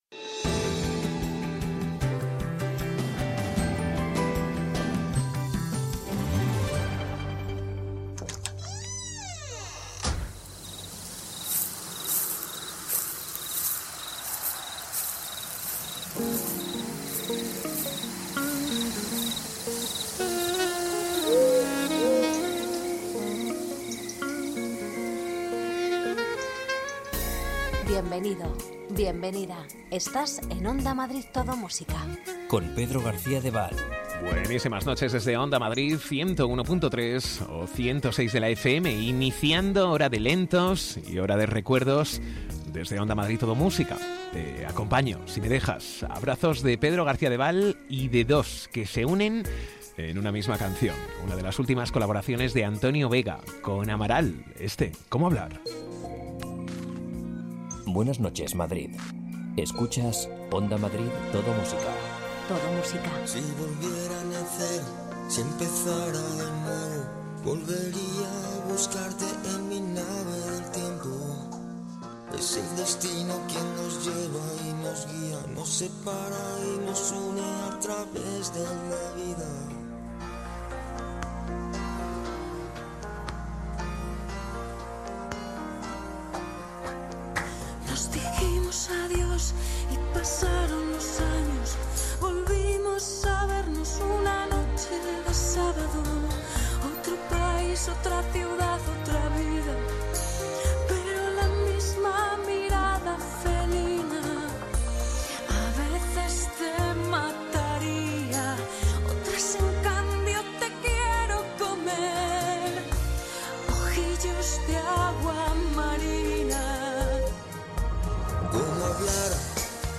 Ritmo tranquilo, sosegado, sin prisas.